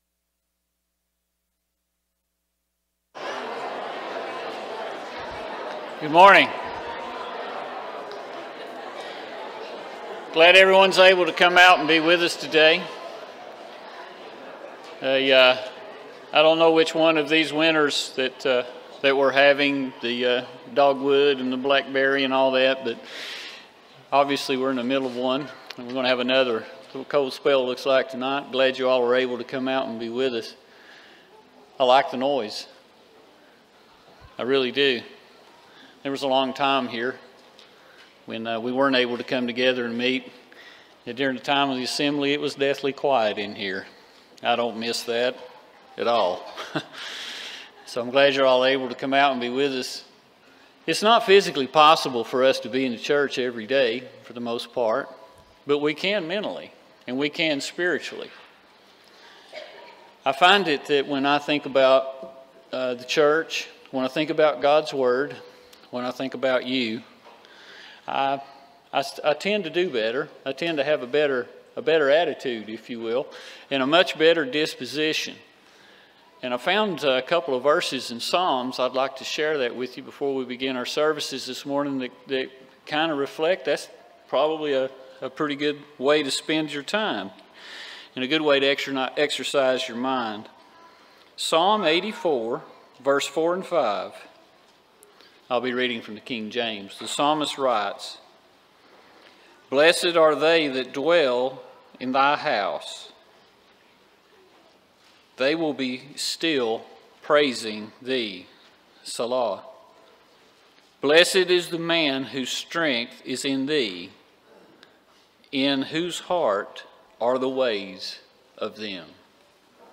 Romans 3:23, English Standard Version Series: Sunday AM Service